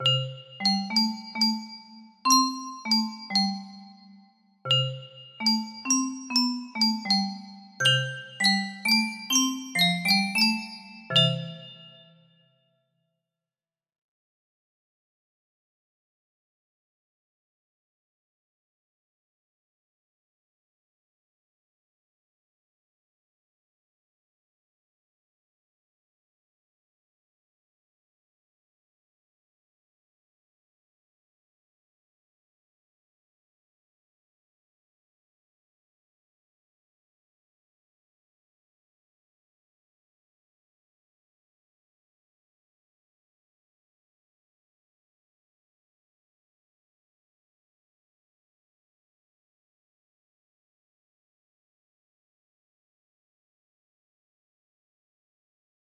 Unknown Artist - Untitled music box melody
Full range 60